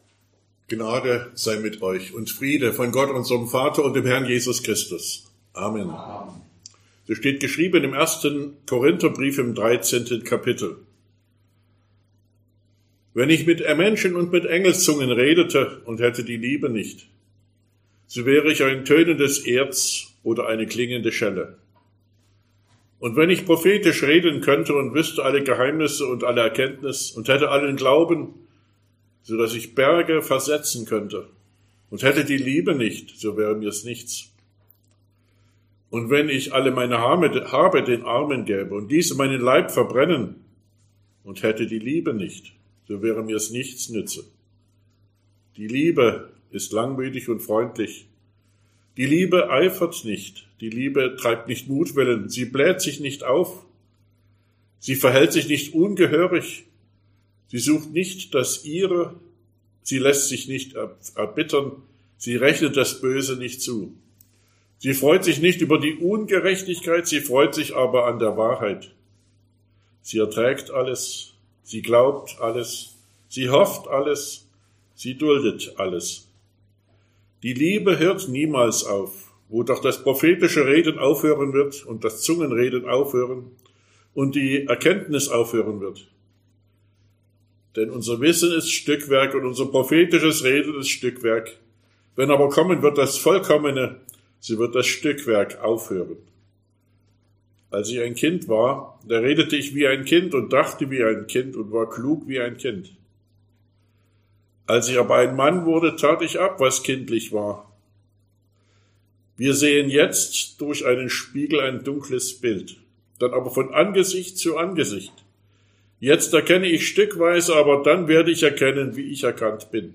Sonntag nach Trinitatis Passage: 1. Korinther 13, 1-13 Verkündigungsart: Predigt « Trinitatisfest 2024 3.